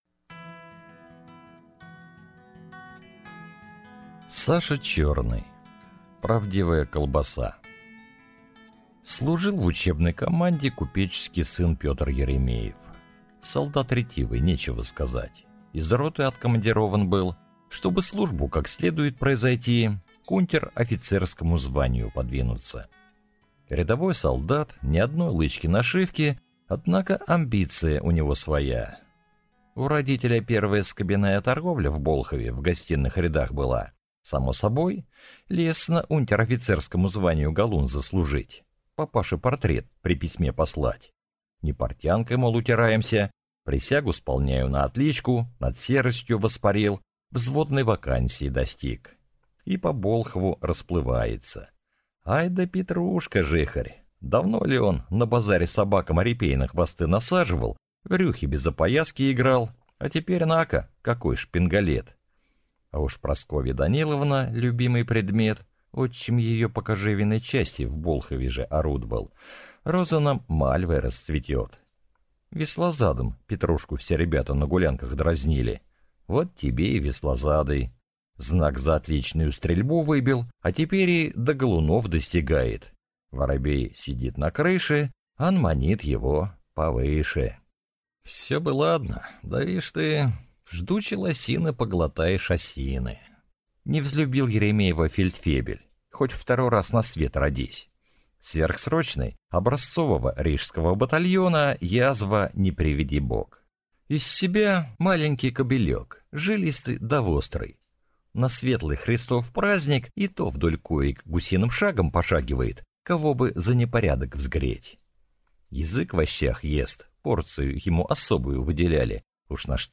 Аудиокнига Правдивая колбаса | Библиотека аудиокниг